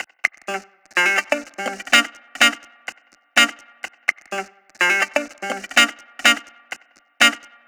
Electric Guitar 11.wav